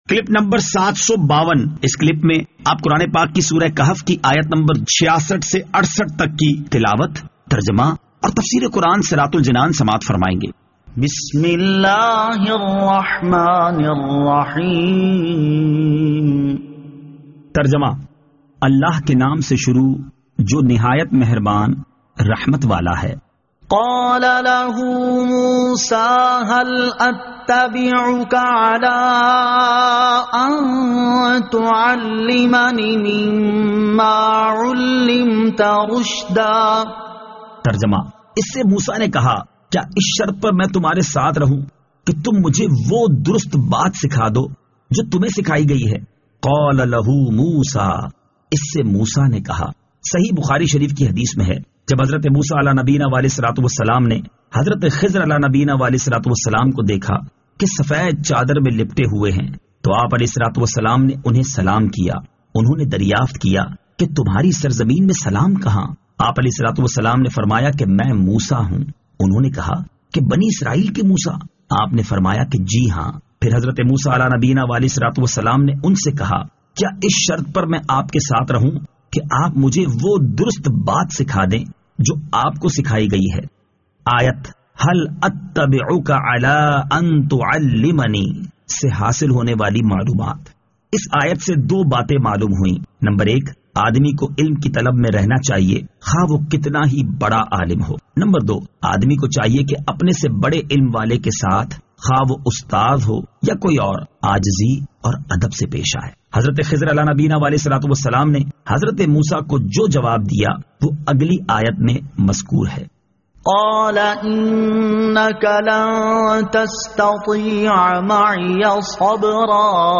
Surah Al-Kahf Ayat 66 To 68 Tilawat , Tarjama , Tafseer